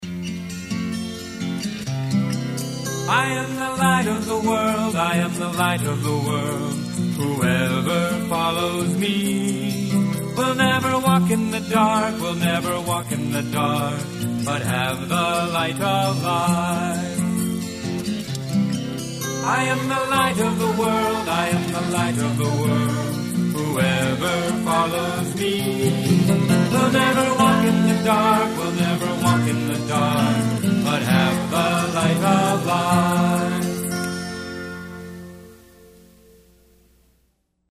Worship Songs For Children